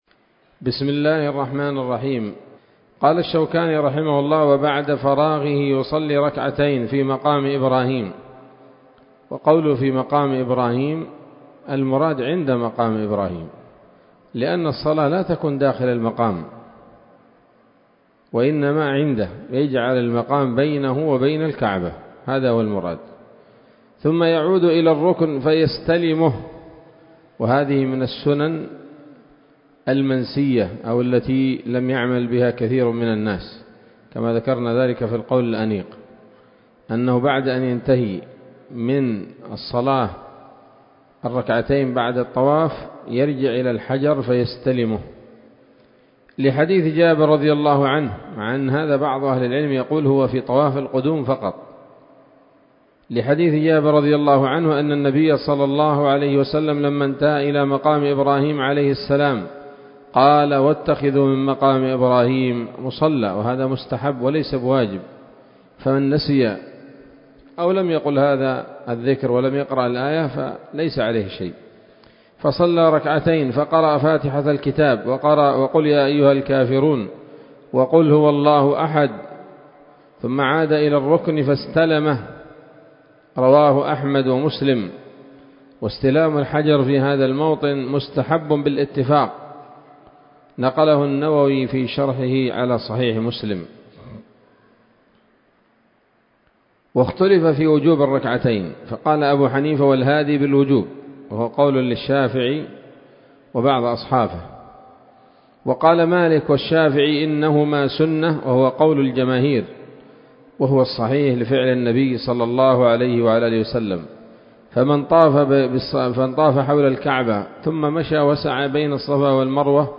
الدرس الخامس عشر من كتاب الحج من السموط الذهبية الحاوية للدرر البهية